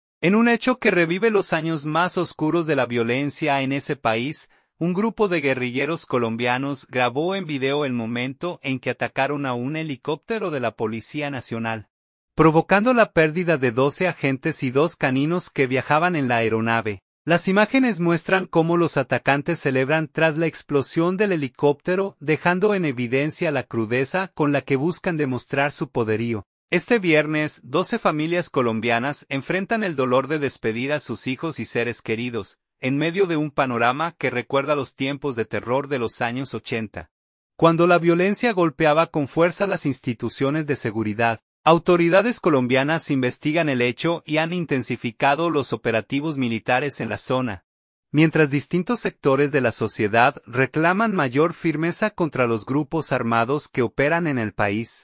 Video | Guerrilleros graban ataque en Colombia: 12 policías y dos caninos pierden la vida
Las imágenes muestran cómo los atacantes celebran tras la explosión del helicóptero, dejando en evidencia la crudeza con la que buscan demostrar su poderío.